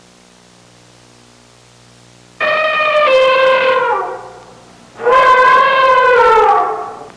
Bull Elephant Sound Effect
bull-elephant.mp3